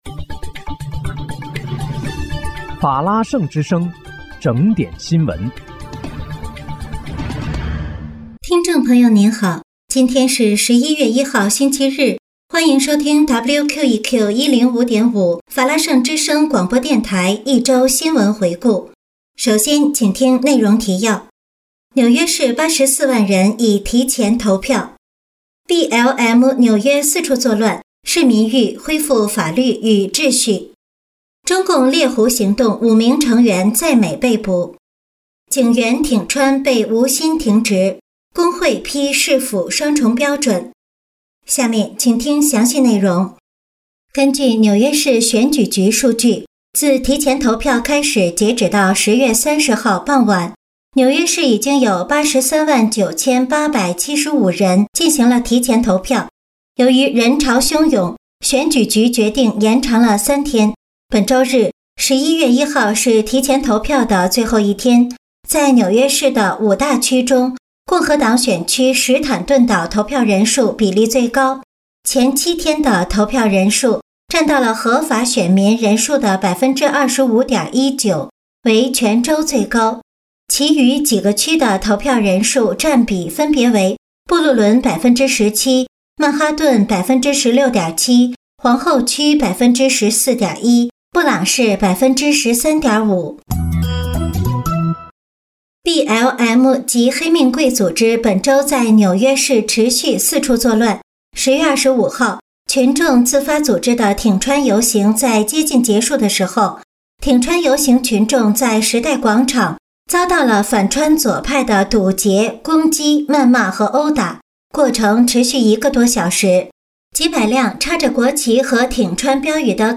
11月1日（星期日）一周新闻回顾